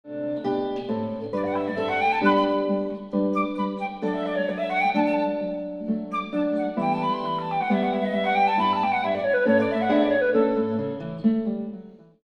flute/guitar
a stereo remix with reverb as heard over only the left and right
I imagine sitting in my 5-ch listening room listening to stereo-speaker replay of a stereo recording of the duo playing in a concert hall.